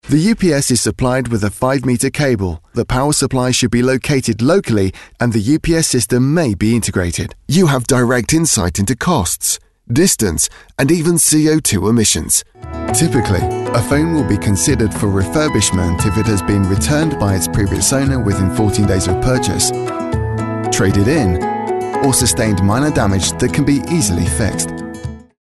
Anglais (Britannique)
Naturelle, Cool, Accessible, Distinctive, Chaude
Vidéo explicative